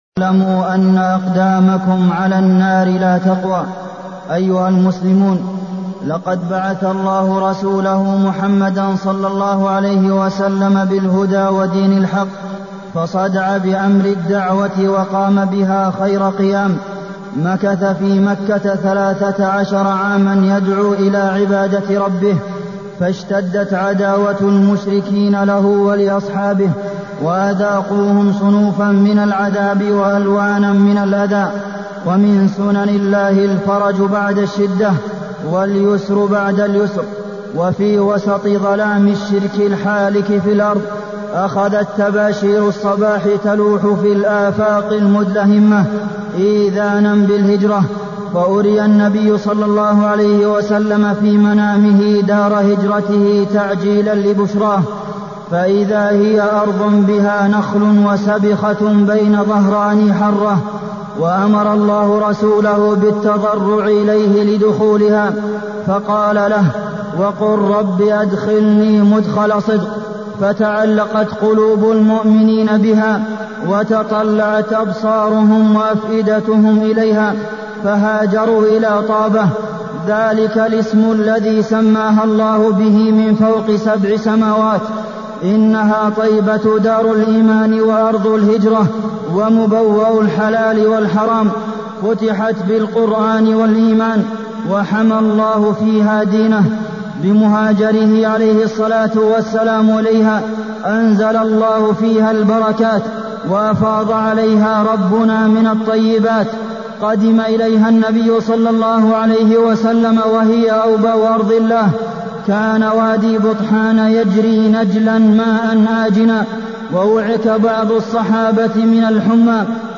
تاريخ النشر ٣ ربيع الثاني ١٤٢٠ هـ المكان: المسجد النبوي الشيخ: فضيلة الشيخ د. عبدالمحسن بن محمد القاسم فضيلة الشيخ د. عبدالمحسن بن محمد القاسم فضل المدينة